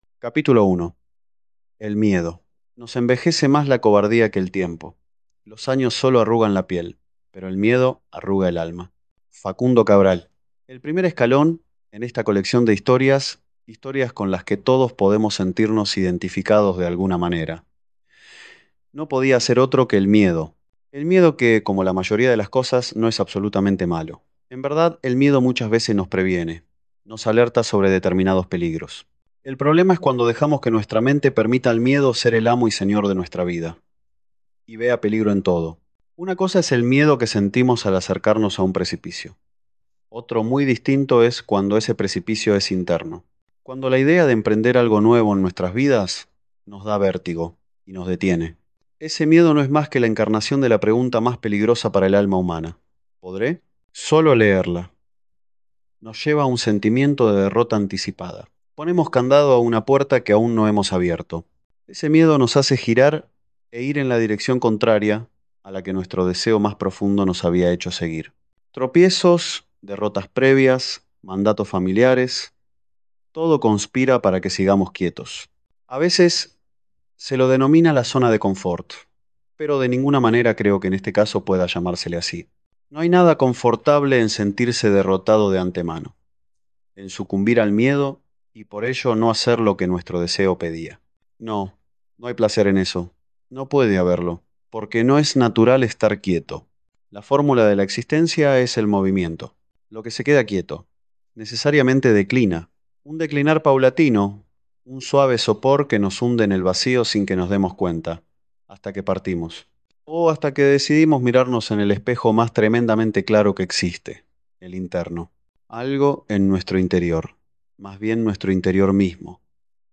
Audiolibro
• ✔ Narrado por el propio autor
audiolibro-capitulo1.mp3